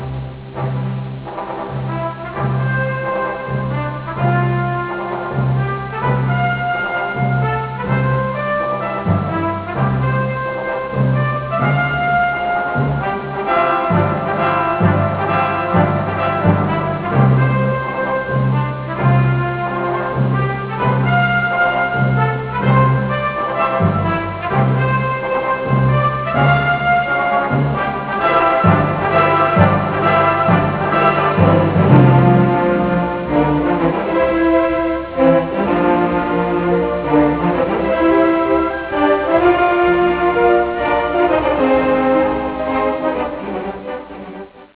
Colonna sonora
Partitura registrata in Inghilterra